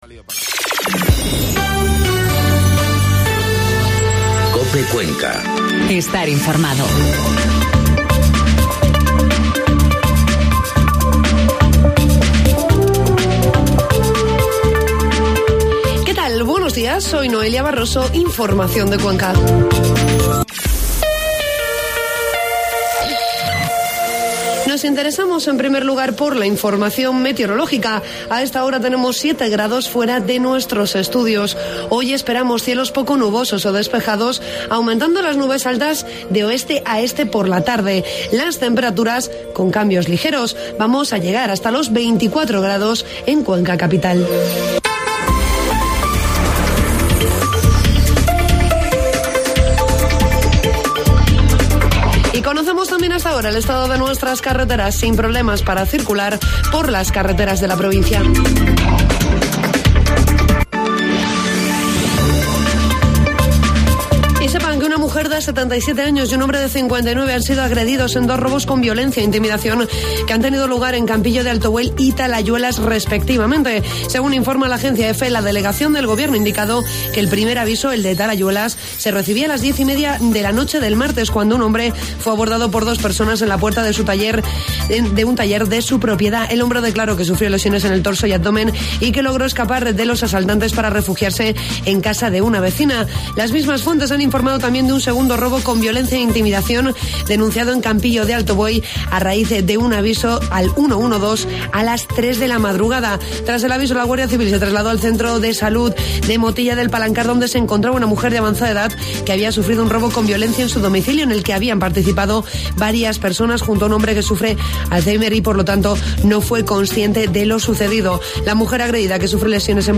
Informativo matinal COPE Cuenca 25 de octubre